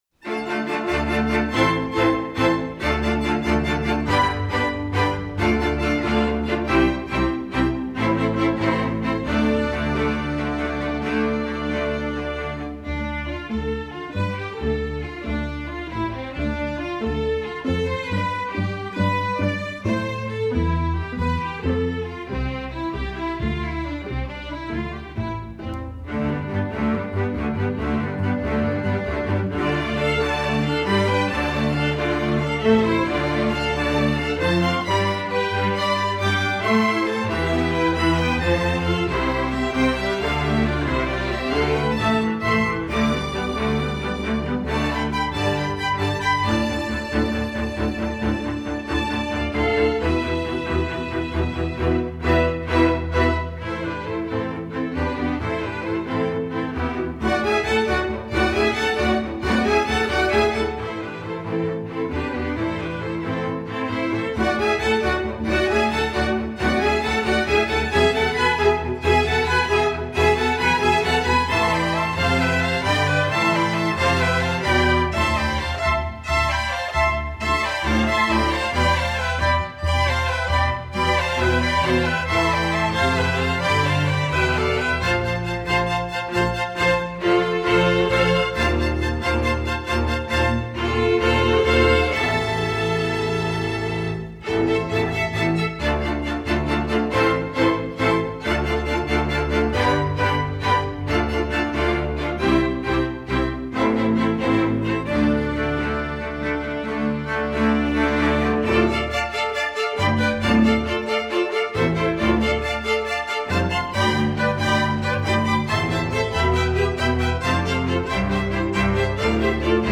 Voicing: Strg Orch